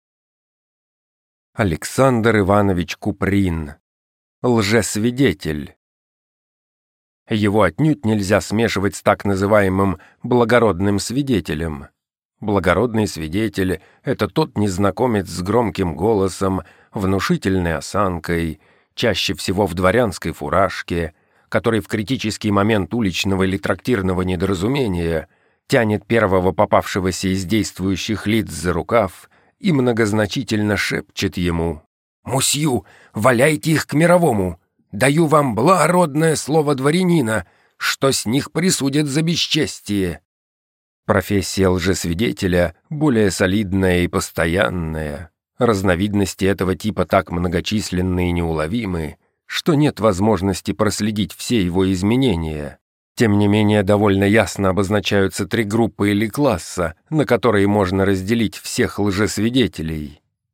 Аудиокнига Лжесвидетель | Библиотека аудиокниг